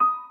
piano_last09.ogg